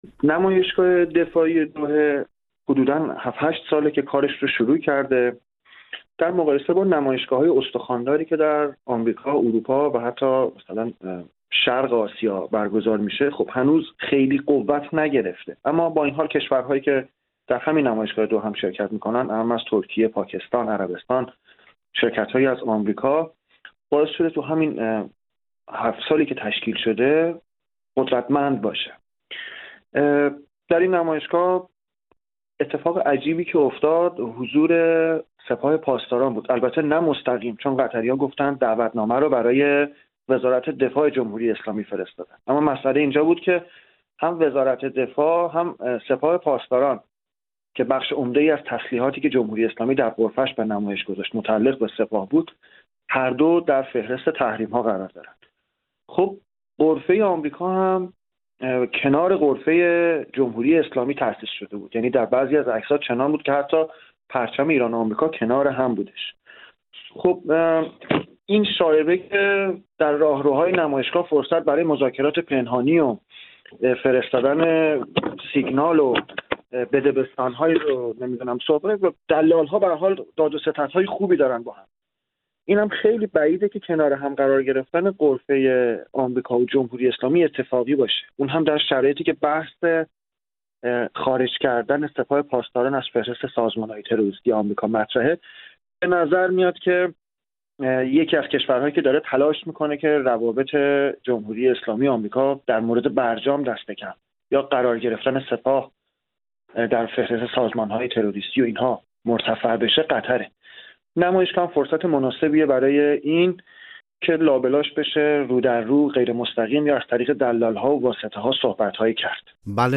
کارشناس نظامی در مورد این نمایشگاه و حضور ایران در آن پرسیدیم.